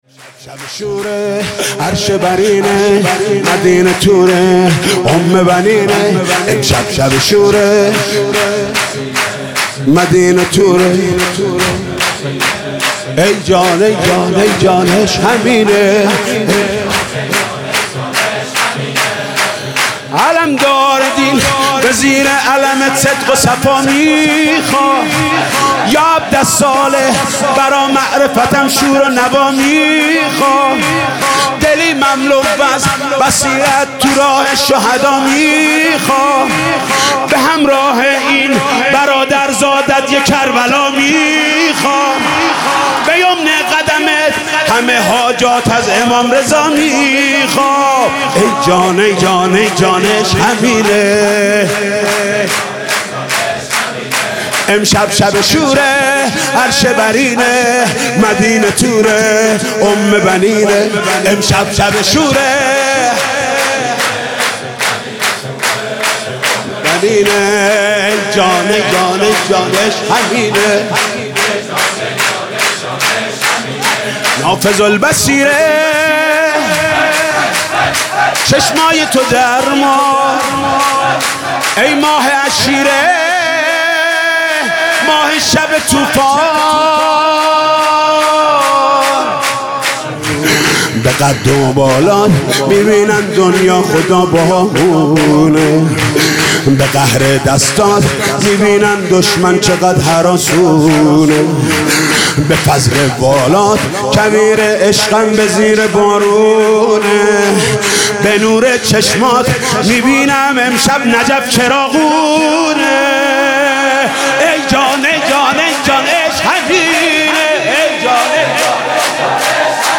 سرود: امشب شب شوره عرش برینه